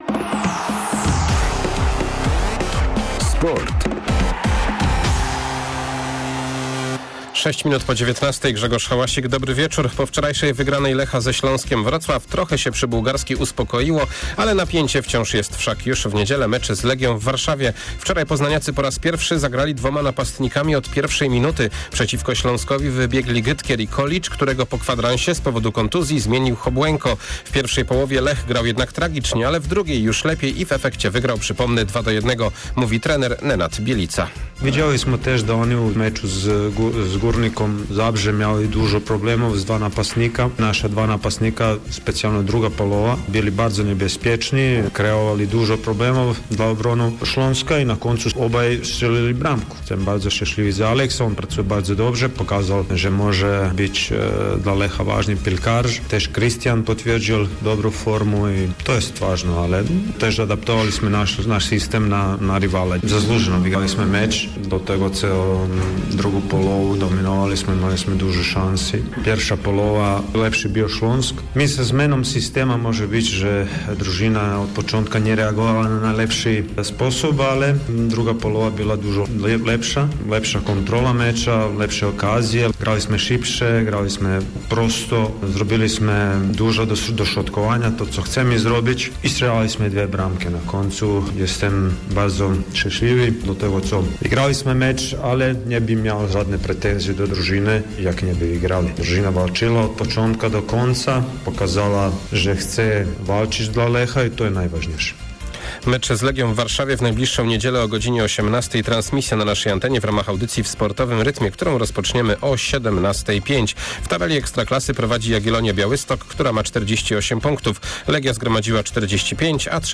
01.03 serwis sportowy godz. 19:05